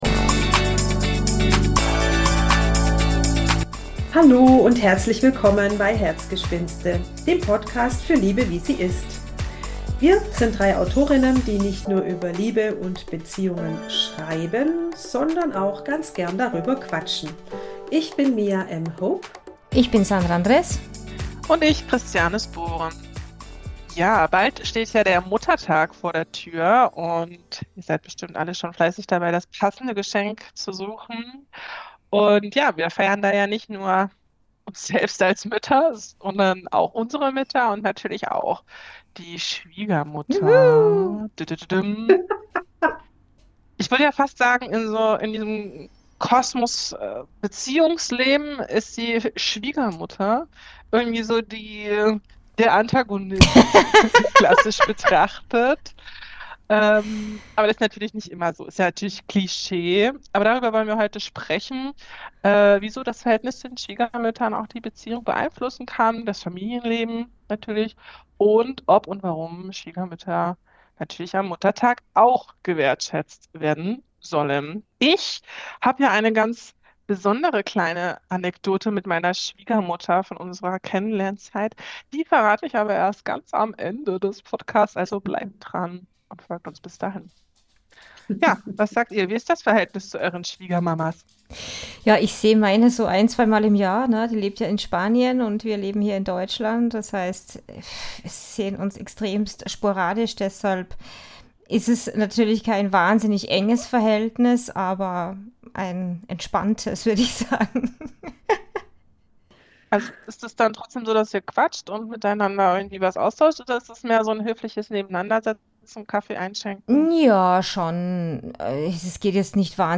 In dieser Folge plaudern wir mit viel Humor über unsere eigenen Erfahrungen, kleine Reibereien, große Herzmomente und die Frage: Muss die Schwiegermutter wirklich immer der Drachen in der Beziehung sein?